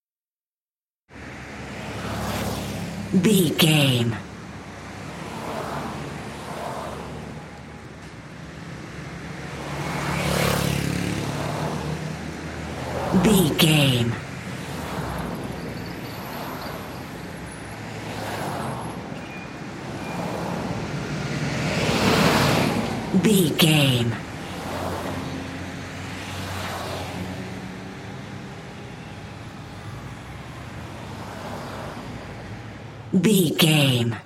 Traffic cars passby
Sound Effects
urban
ambience